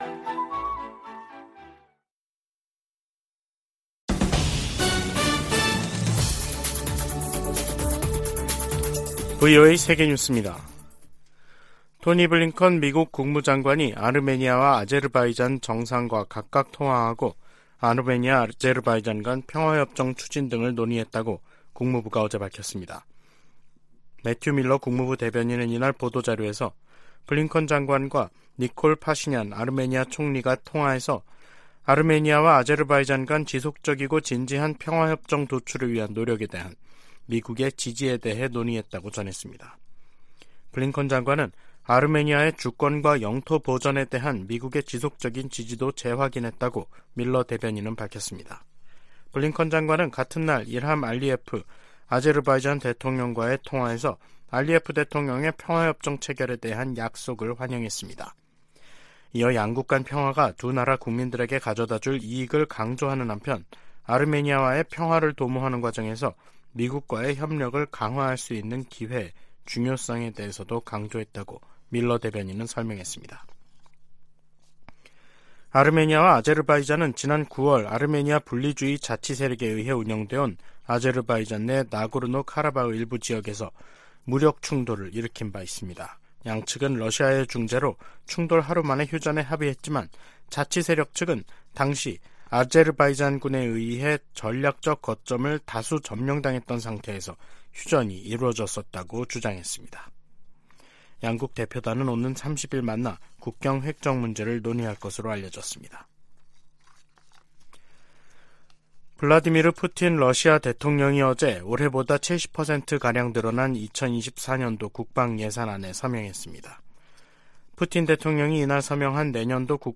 VOA 한국어 간판 뉴스 프로그램 '뉴스 투데이', 2023년 11월 28일 2부 방송입니다. 북한은 군사정찰위성 ‘만리경 1호’가 백악관과 군 기지 등 미국 본토 내 주요 시설을 촬영했다고 주장했습니다.